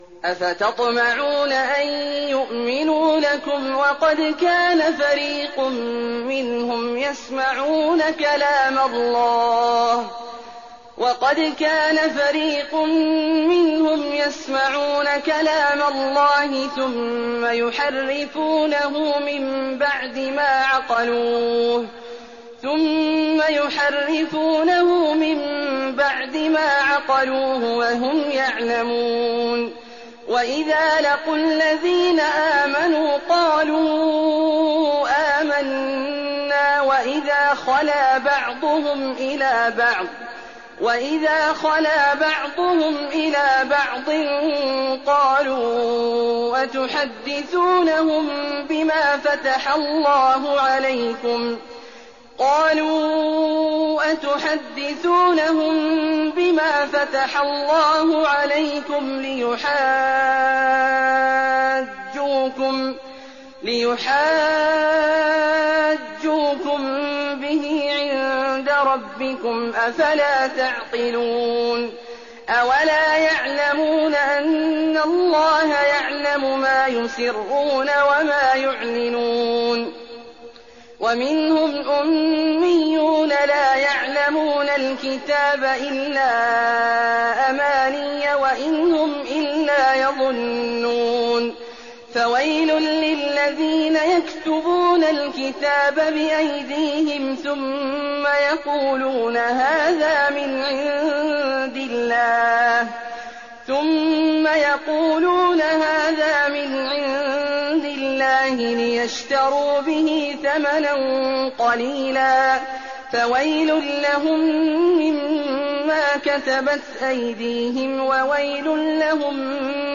تراويح الليلة الأولى رمضان 1419هـ من سورة البقرة (75-141) Taraweeh 1st night Ramadan 1419H from Surah Al-Baqara > تراويح الحرم النبوي عام 1419 🕌 > التراويح - تلاوات الحرمين